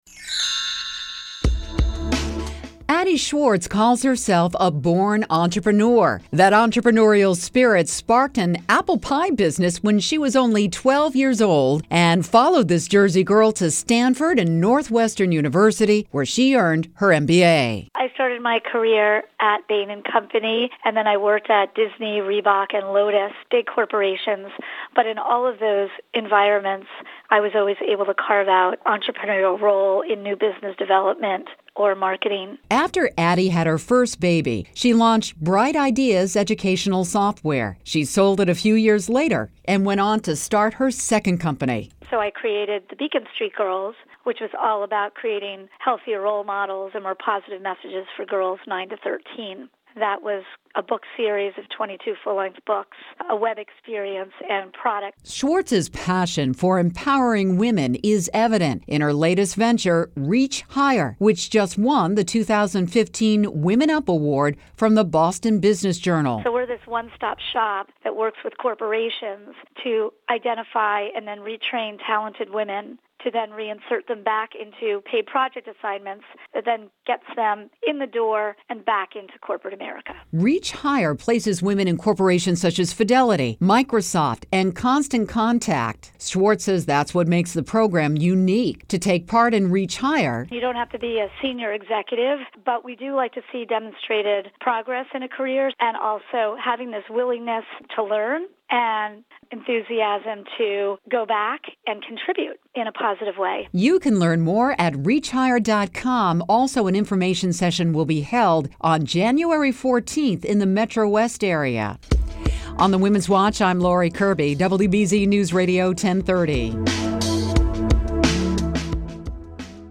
WBZ Radio Interview